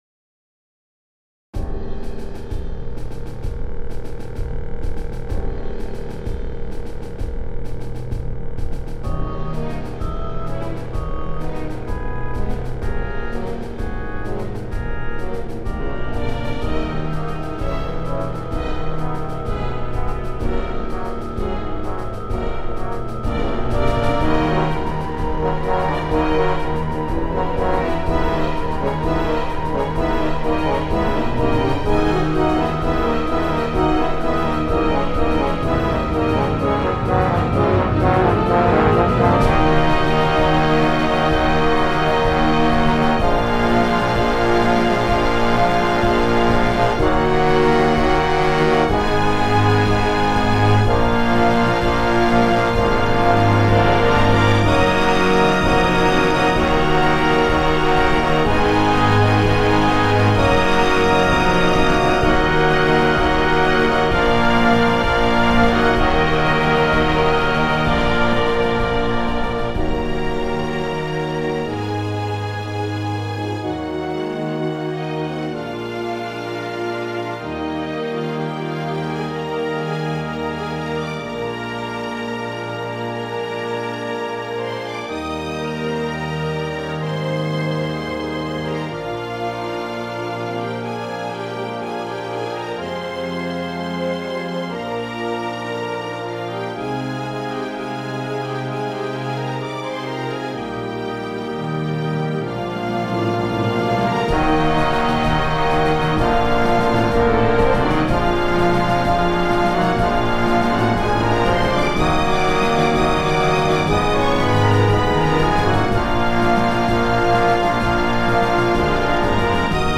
Beginn des 2. Aktes der Oper
Oper in 4 Akten